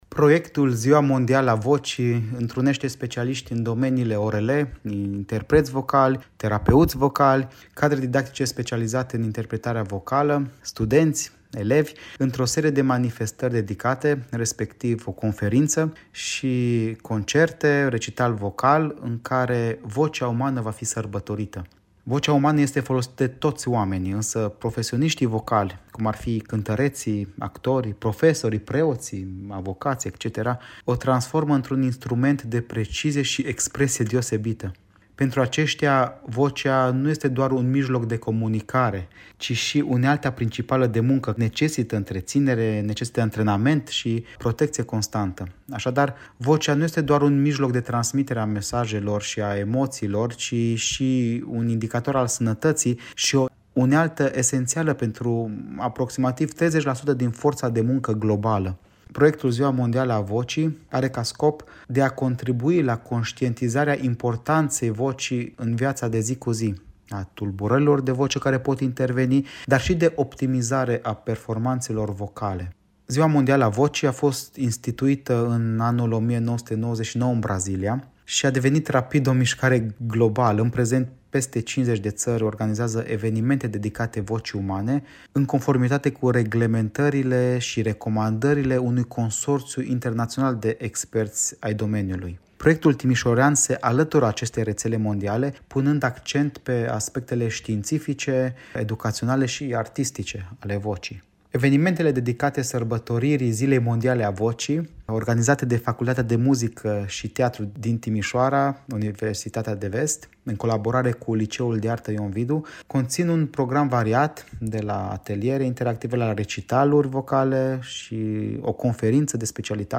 Interviu / lect. univ. dr. habil.